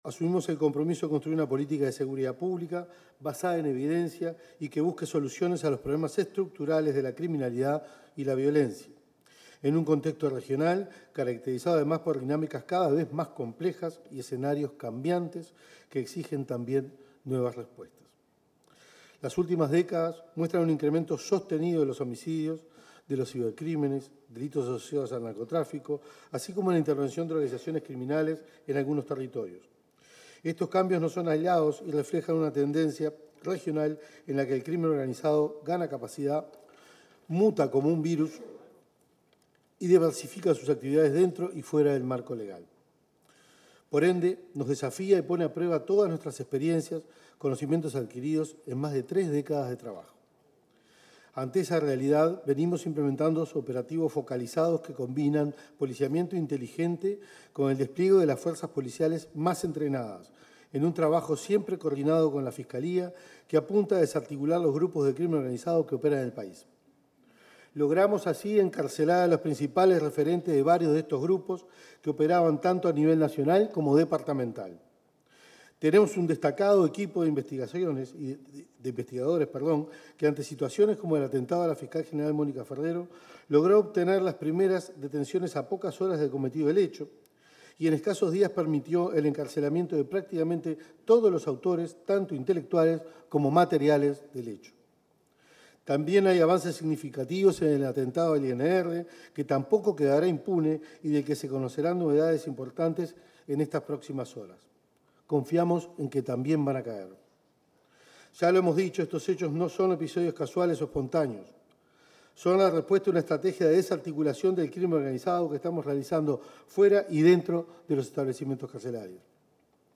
En el el marco del cierre de los Encuentros por Seguridad, el ministro del Interior, Carlos Negro, fue entrevistado para medios periodísticos.